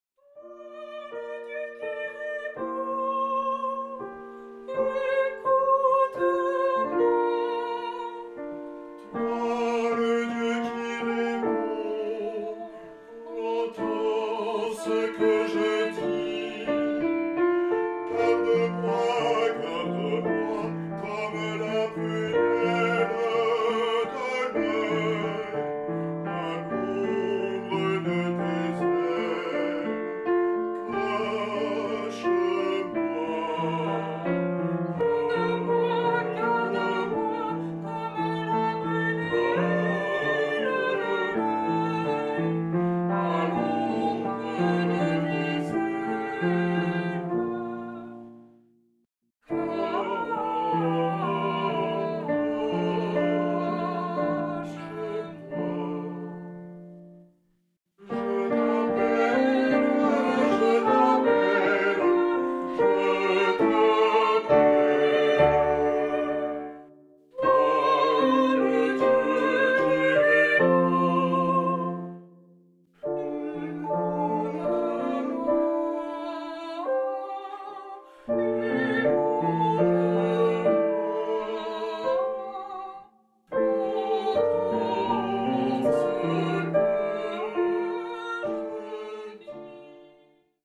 Tonart(en): g-moll